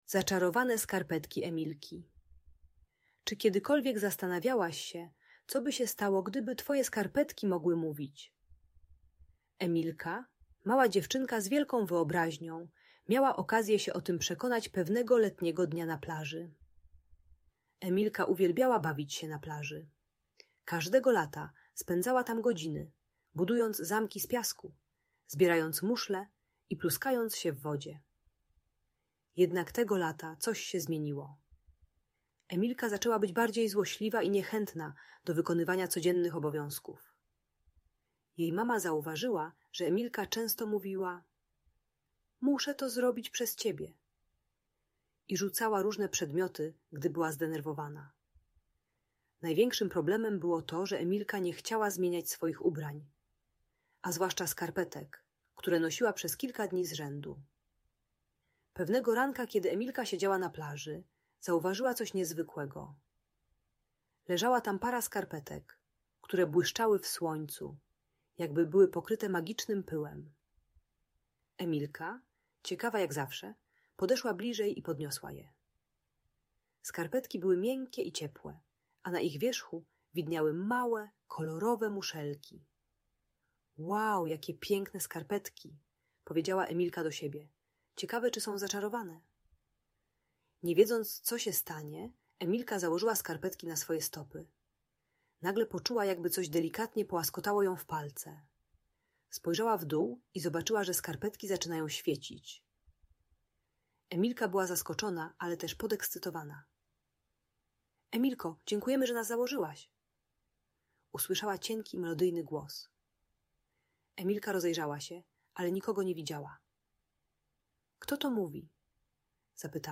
Zaczarowane Skarpetki Emilki - Agresja do rodziców | Audiobajka